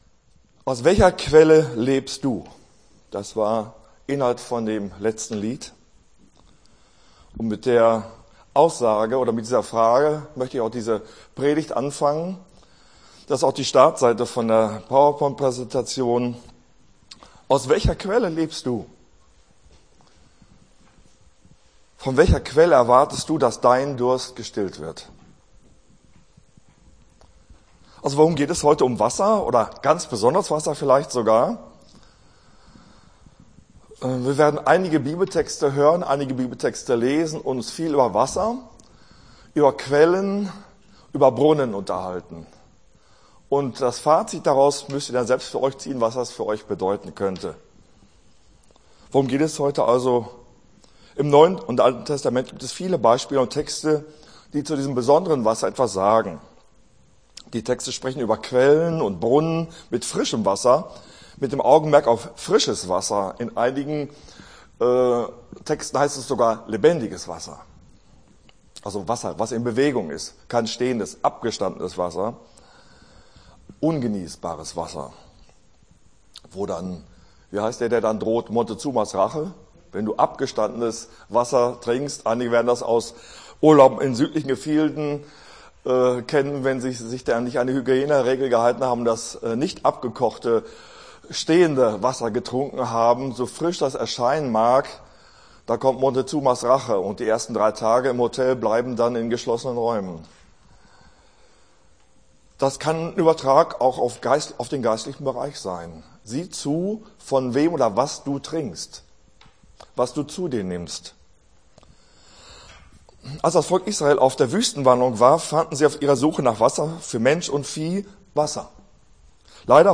Gottesdienst 10.03.24 - FCG Hagen